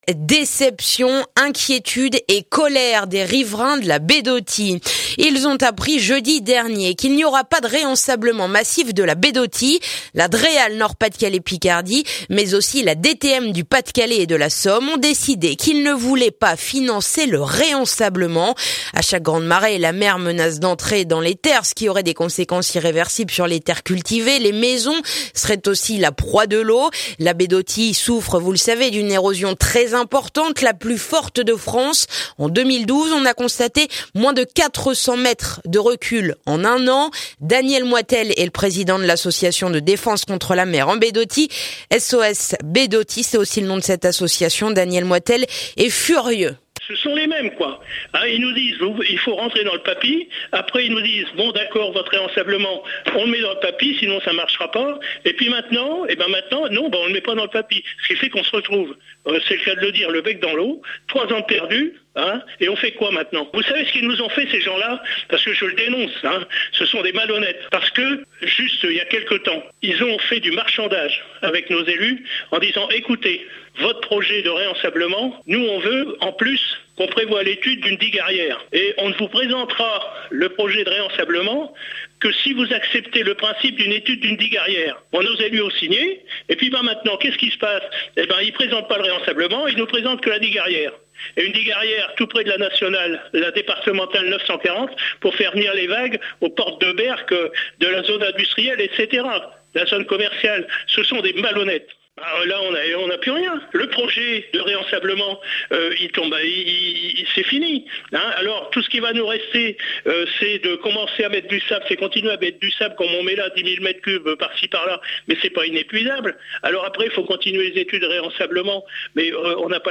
L'association vient aussi d'envoyer une lettre à la Ministre de l'écologie Ségolène Royale. Reportage